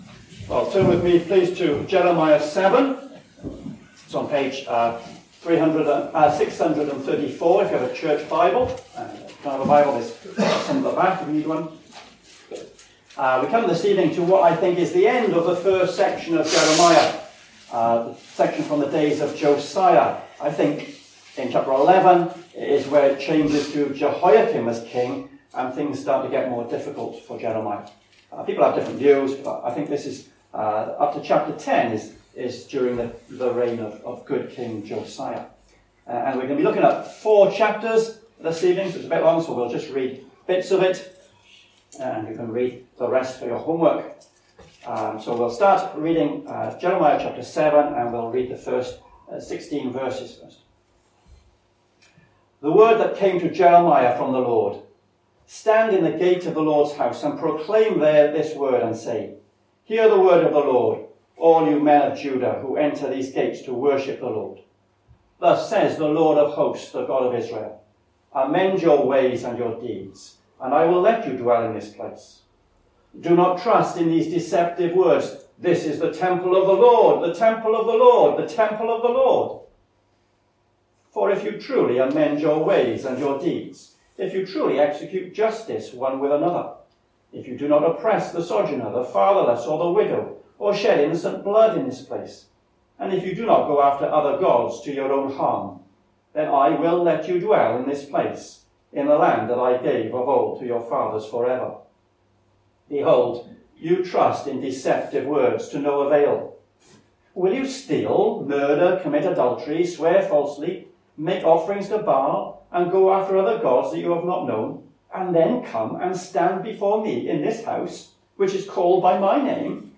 A link to the video recording of the 6:00pm service and an audio recording of the sermon.